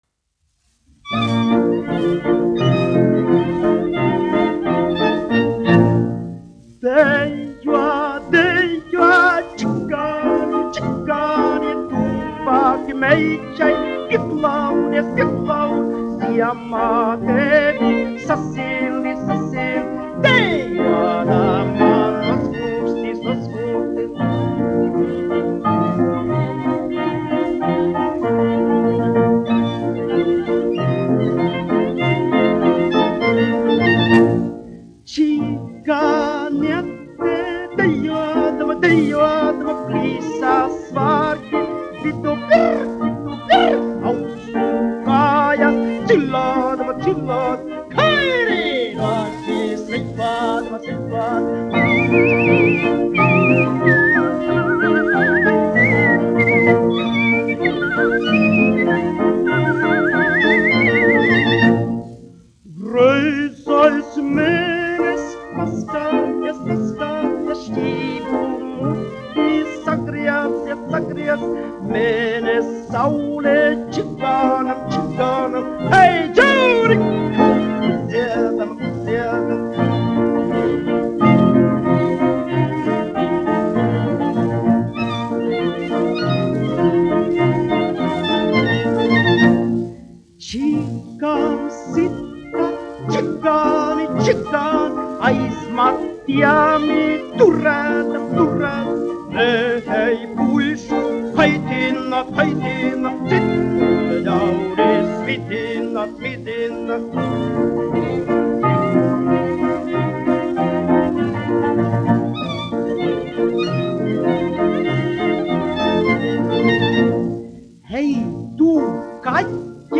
1 skpl. : analogs, 78 apgr/min, mono ; 25 cm
Čehu tautasdziesmas
Skaņuplate